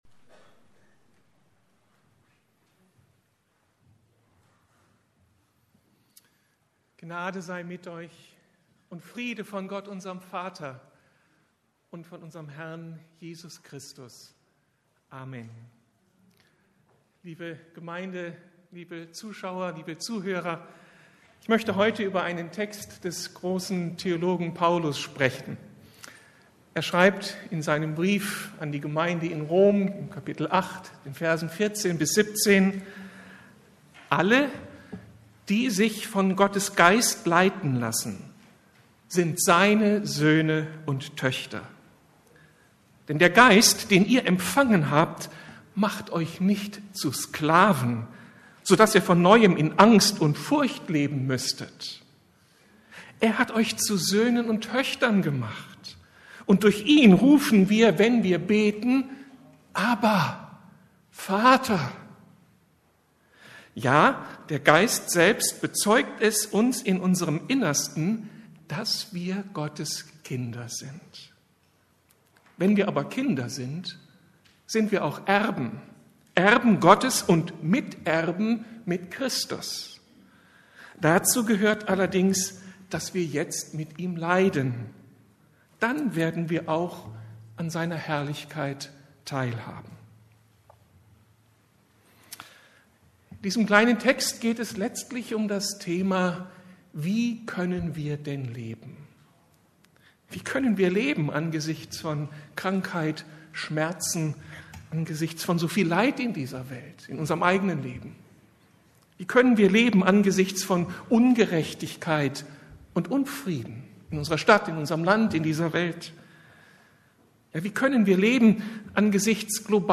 Beziehung tut gut! ~ Predigten der LUKAS GEMEINDE Podcast